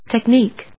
tech・nique /tekníːk/
• / tekníːk(米国英語)